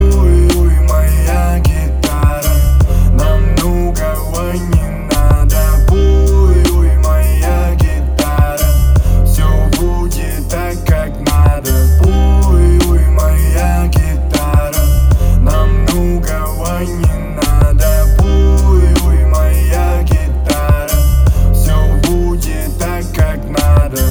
позитивные
красивые
русский рэп